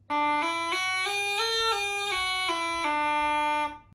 На этой странице собраны звуки жалейки — старинного русского инструмента с характерным тембром.
Жалейка (музыкальный инструмент) - Альтернативный звук